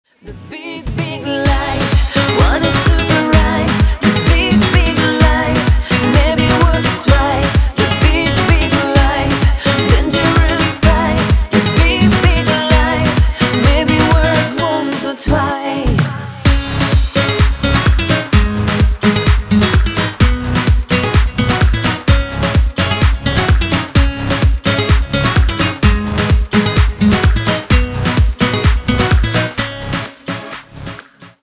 • Качество: 320, Stereo
гитара
громкие
Radio edit
красивый женский вокал
house
Зарубежный поп. Заставляет двигаться)